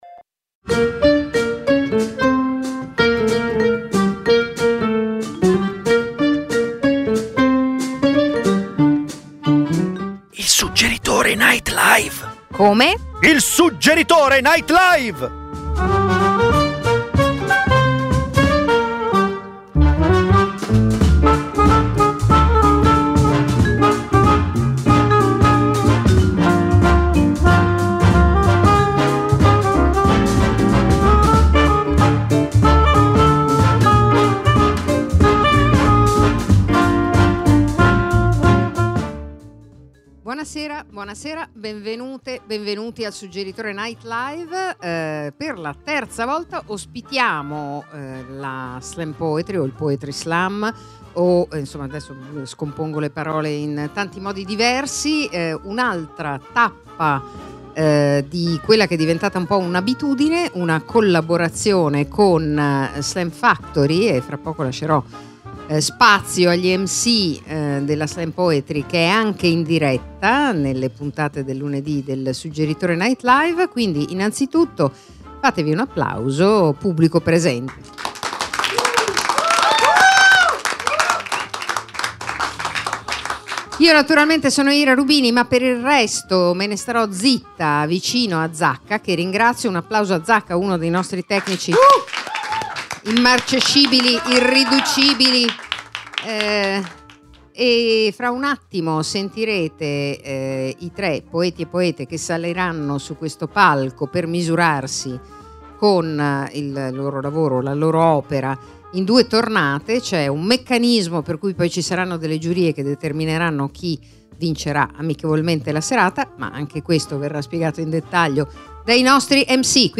Il Suggeritore Night Live, ogni lunedì dalle 21:30 alle 22:30 dall’Auditorium Demetrio Stratos, è un night talk-show con ospiti dello spettacolo dal vivo che raccontano e mostrano estratti dei loro lavori. Gli ascoltatori possono partecipare come pubblico in studio a partire dalle 21.00. E spesso, il Suggeritore NL vi propone serate speciali di stand up, slam poetry, letture di drammaturgia contemporanea, imprò teatrale.